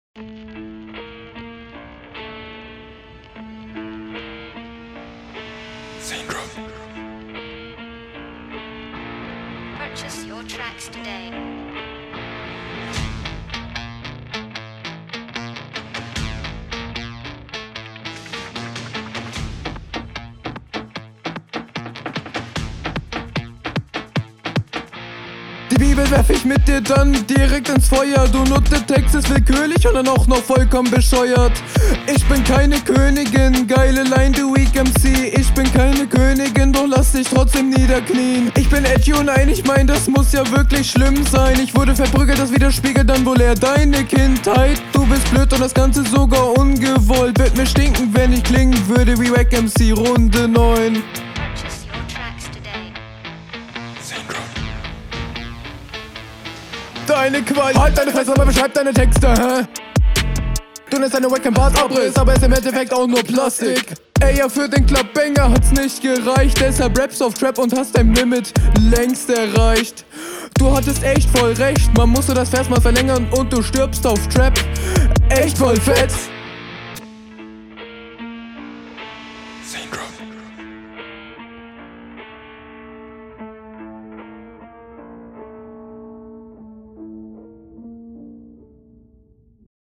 flowlich bist du hier deinem gegner unterlegen, du wirkst teilweise sehr unsicher und holprig. vielleicht …